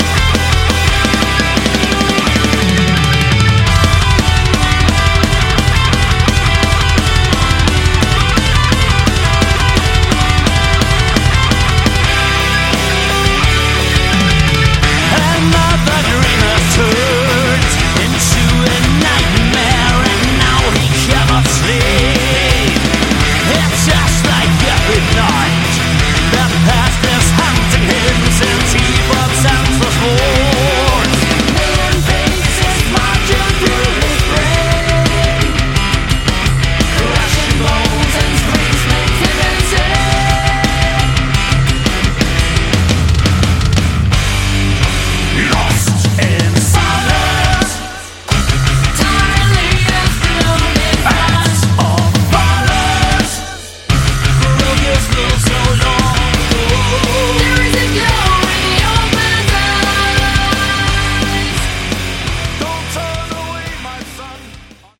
Category: Melodic Metal
guitars
vocals
bass
drums
keyboards